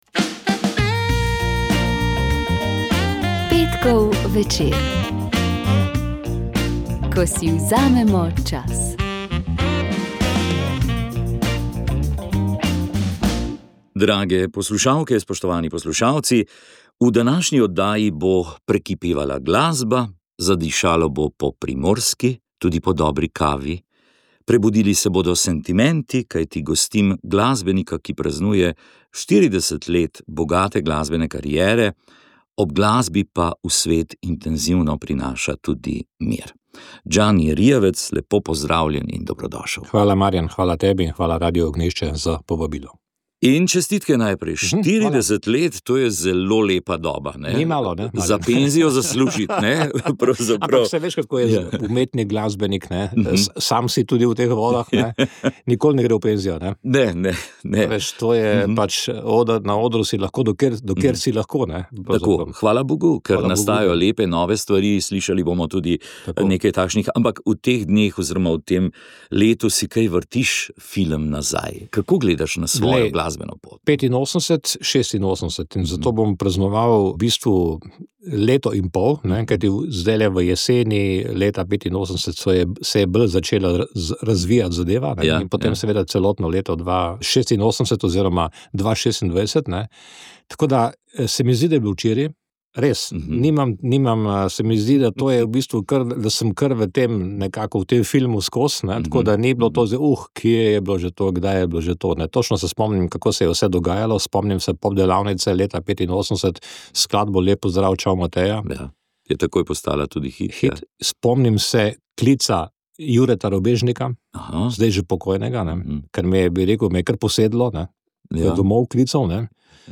Poročali smo, kako gre romarjem, ki so ob 800-letnici Sončne pesmi vzeli v roke popotno palico in se peš odpravili iz Goričkega v Piran. Približno na polovici poti jih je naš mikrofon ujel pri kapucinih v Štepanji vasi.